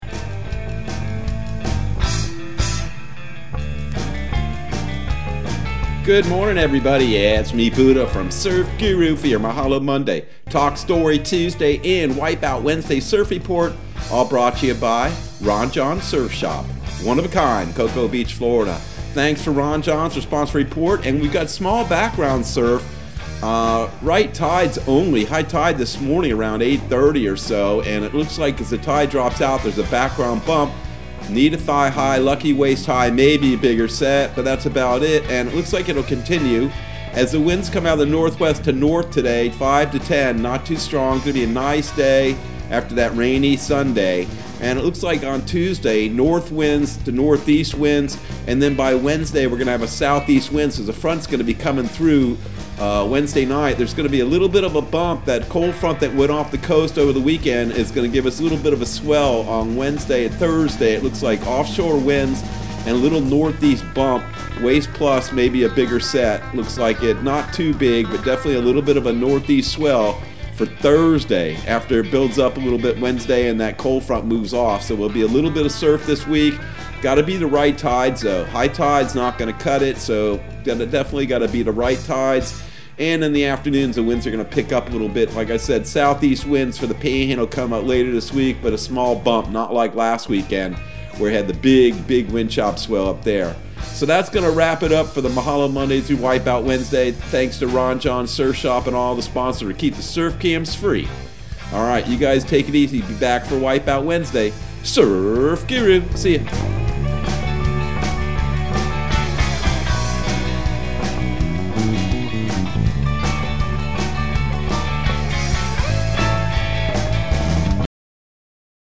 Surf Guru Surf Report and Forecast 04/12/2021 Audio surf report and surf forecast on April 12 for Central Florida and the Southeast.